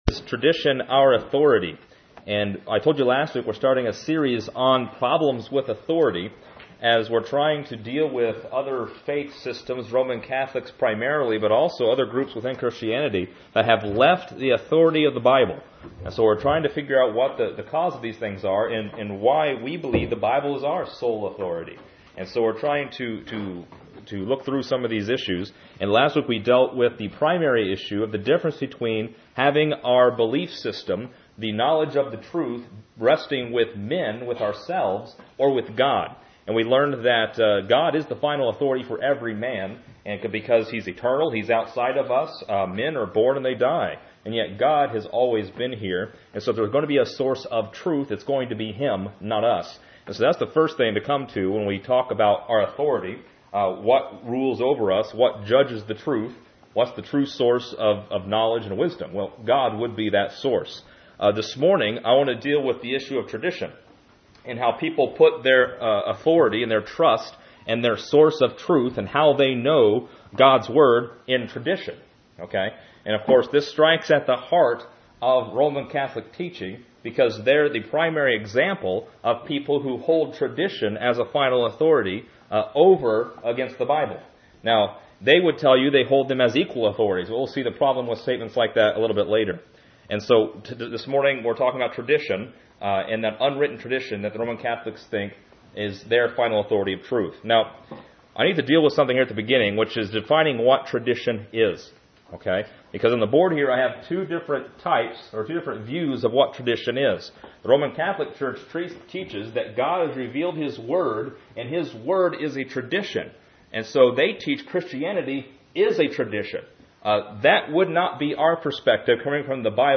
This is lesson 2 in our series on Problems With Authority